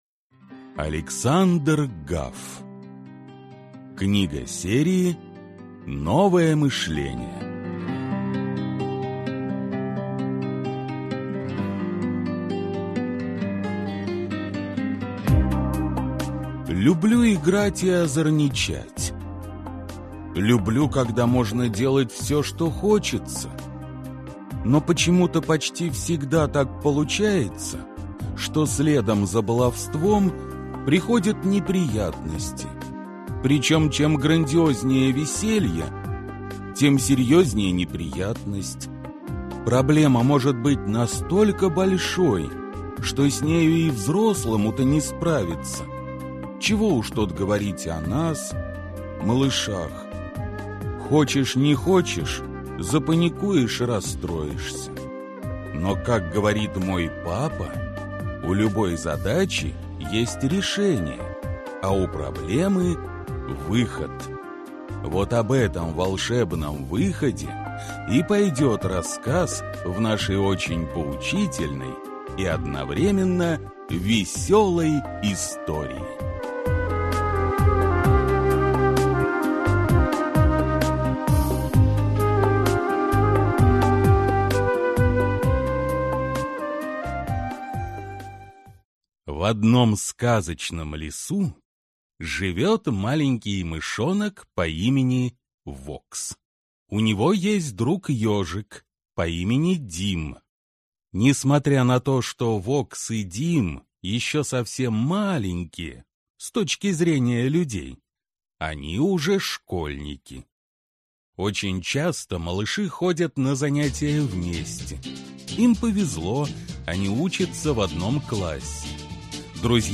Аудиокнига Мышонок Вокс и план СБ | Библиотека аудиокниг